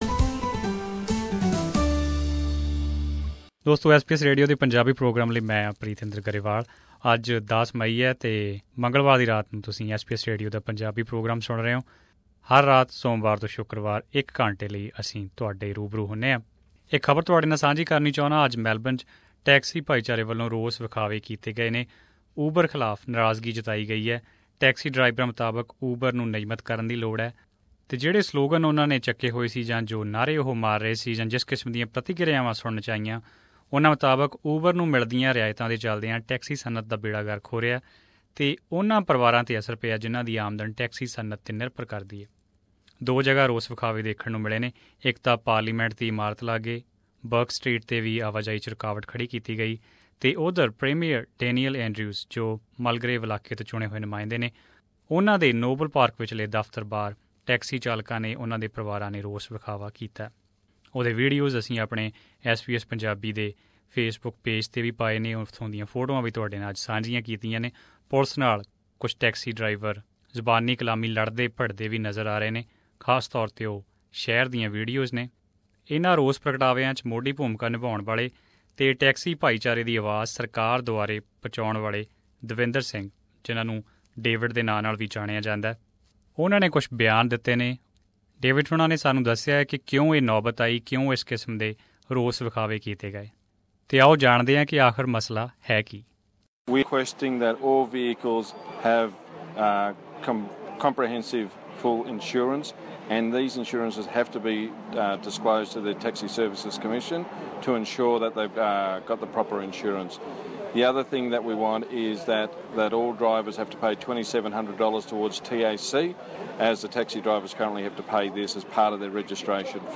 SBS Punjabi